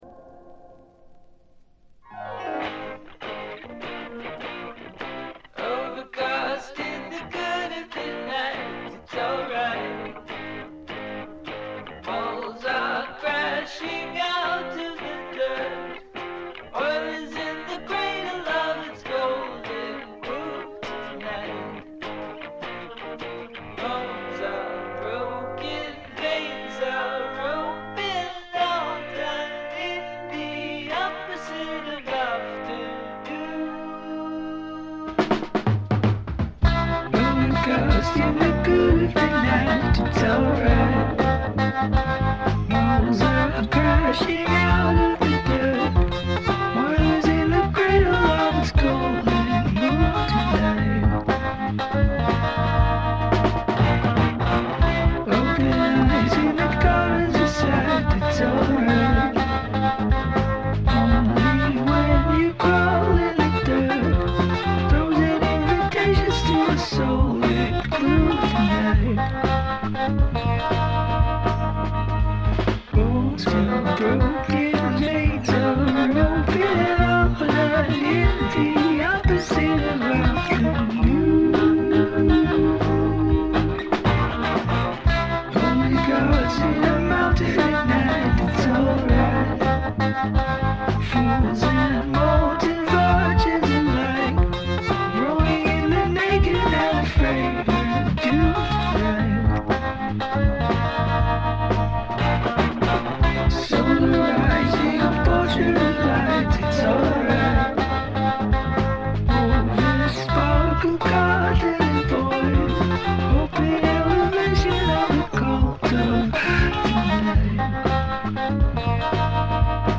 試聴曲は現品からの取り込み音源です。
Drums